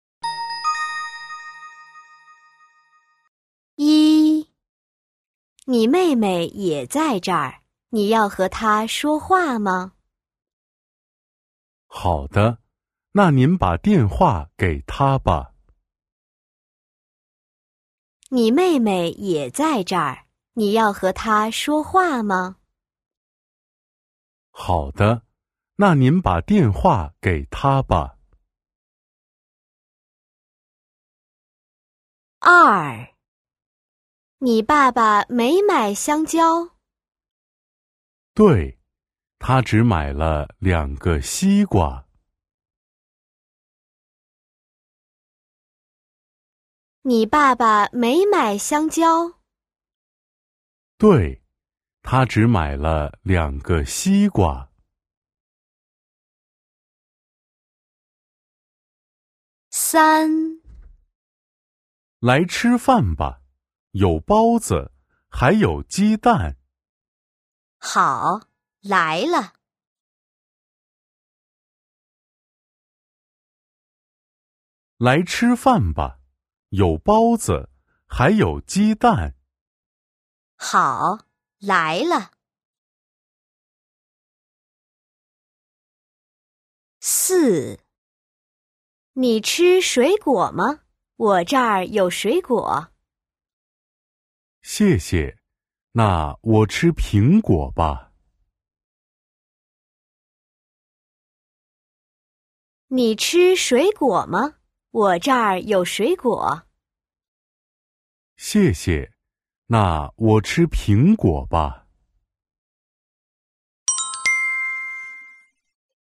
Kĩ năng nghe